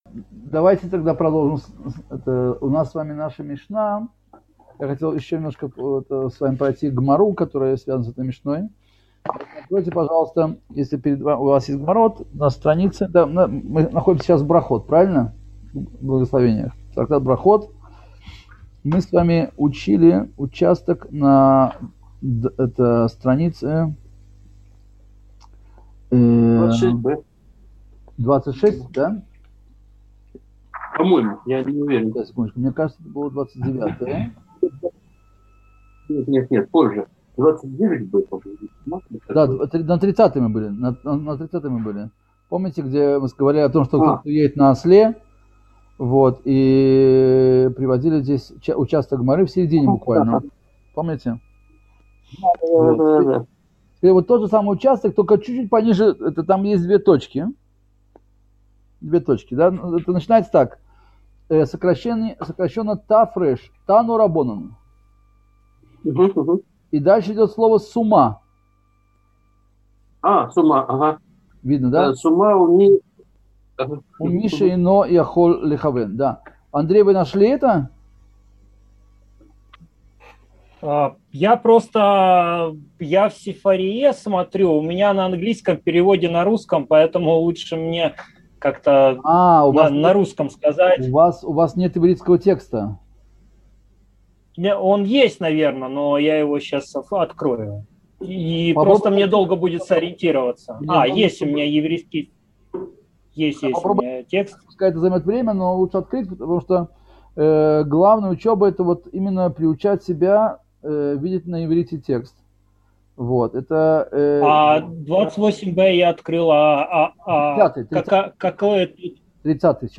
Цикл уроков по изучению мишны Брахот